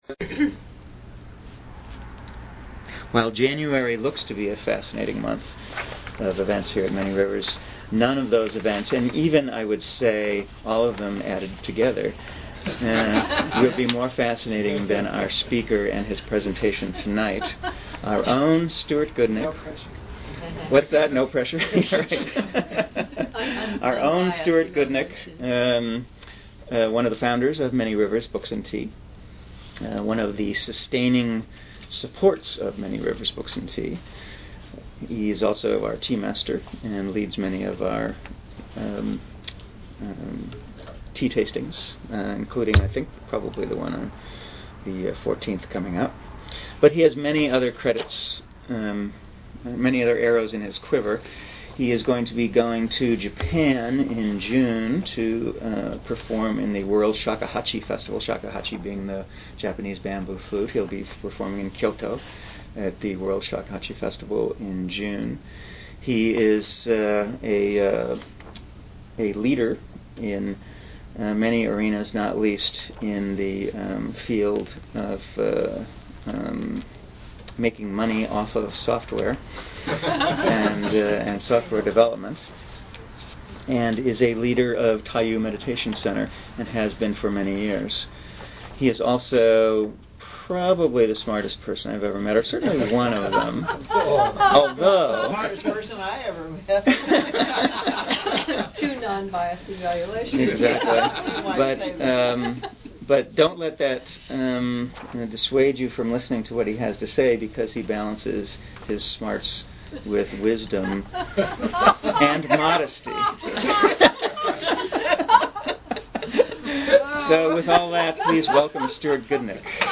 Archive of an event at Sonoma County's largest spiritual bookstore and premium loose leaf tea shop.
Join us for a lively discussion on integrity in the game of spiritual practice, and together we will explore and discover how this seemingly simply concept can actually unlock a powerful access to Self transformation.